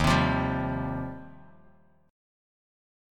D#6add9 chord